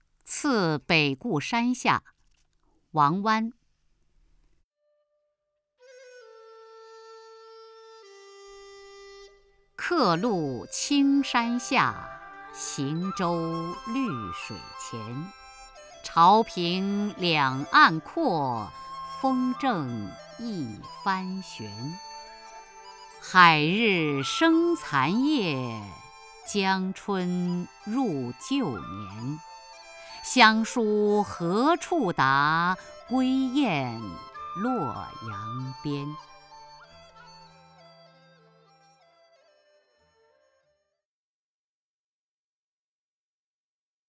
雅坤朗诵：《次北固山下》(（唐）王湾) （唐）王湾 名家朗诵欣赏雅坤 语文PLUS